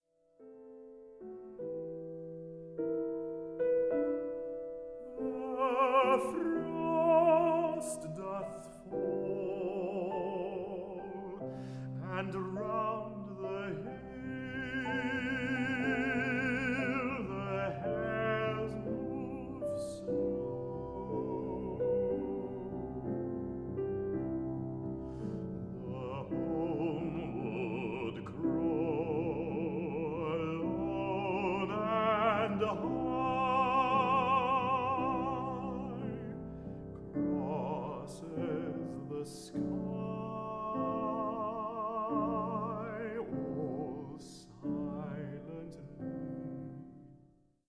Stereo
baritone
piano